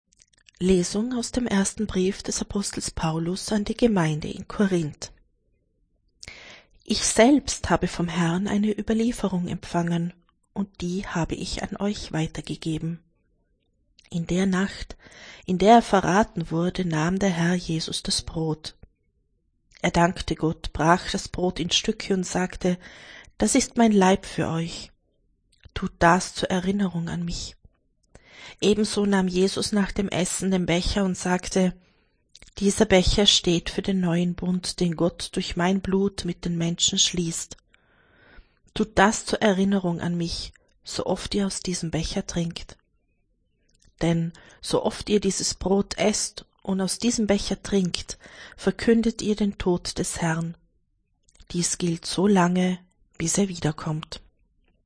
Wenn Sie den Text der 2. Lesung aus dem Brief des Apostels Paulus an die Gemeinde in Korínth anhören möchten: